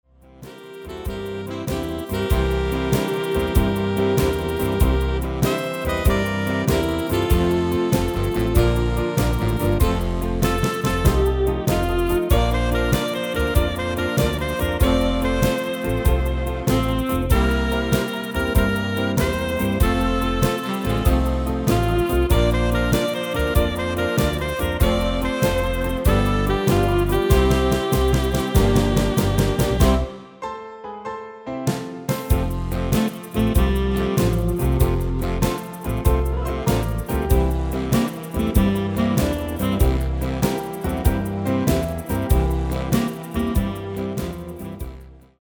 Demo/Koop midifile
Taal uitvoering: Instrumentaal
Genre: Evergreens & oldies
Toonsoort: F
Originele song is instrumentaal